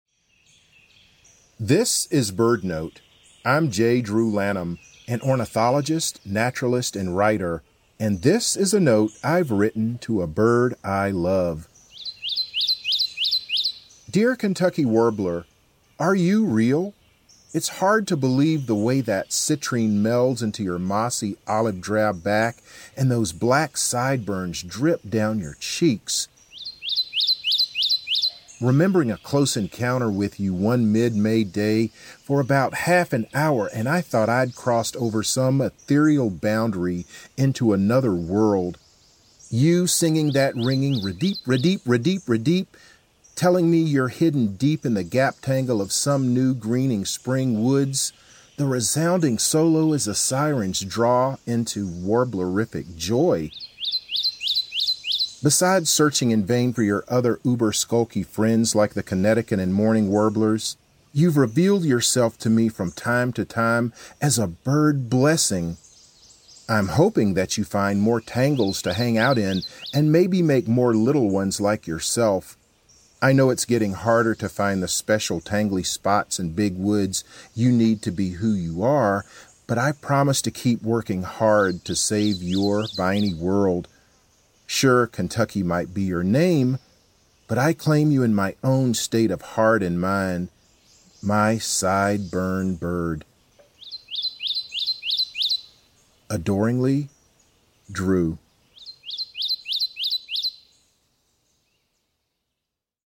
In this episode, ornithologist J. Drew Lanham reads a letter he has written to a Kentucky Warbler, an “uber-skulky” species that’s hard to find but brings “warbler-iffic joy” when Drew hears them.